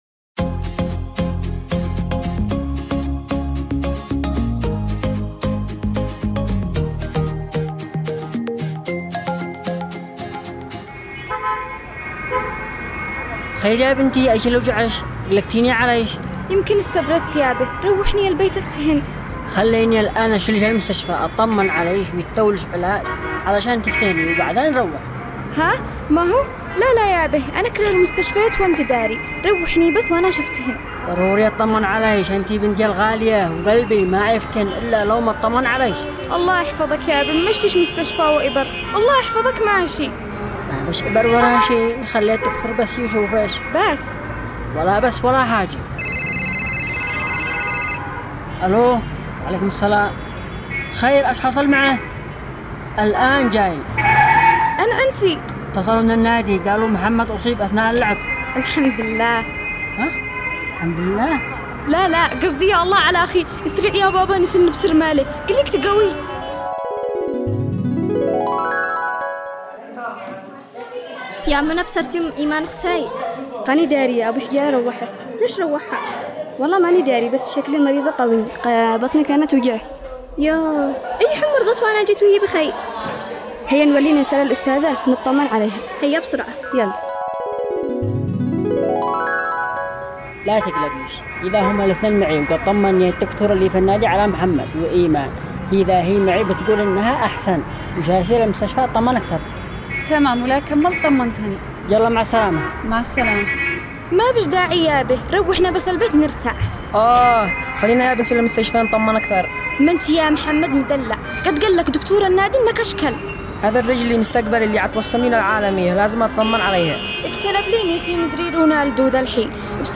دراما رمضانية - عائلة مصطفى